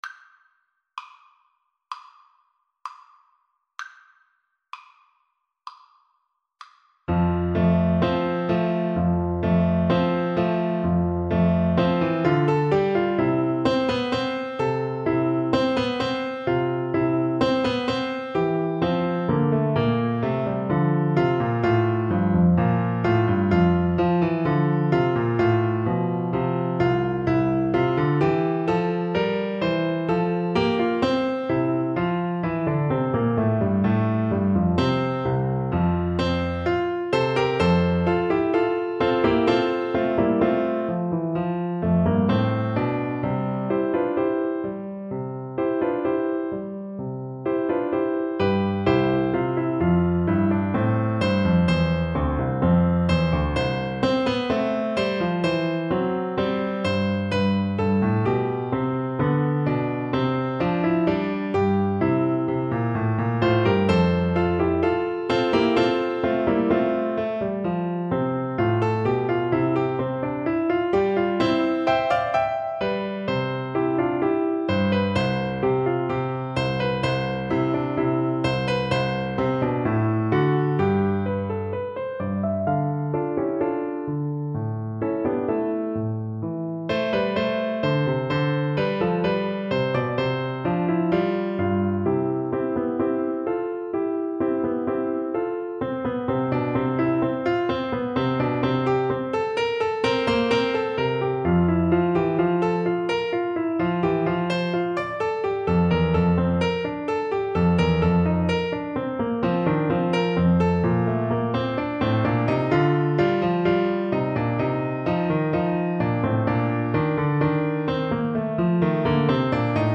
Clarinet version
2/2 (View more 2/2 Music)
[Allegro moderato = c.90] (View more music marked Allegro)
Classical (View more Classical Clarinet Music)